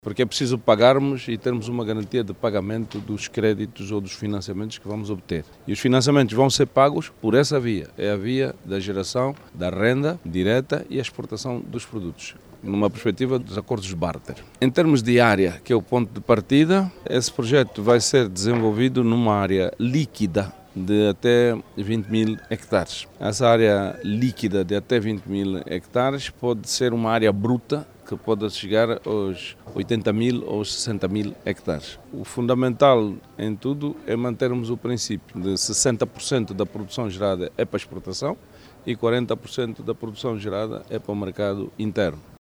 Angola e Alemanha, assinaram no ontem, quarta-feira(05),  acordos que vão permitir captar investimentos para a construção de polos agroindustriais. O Ministro Agricultura e Florestas, Isaac dos Anjos, diz que o projecto, vai ser desenvolvido numa área bruta de 80 mil hectares, sendo que 60 por cento da produção, vai ser para a exportação e 40 por cento para consumo interno.